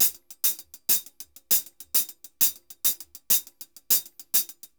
HH_Samba 100_1.wav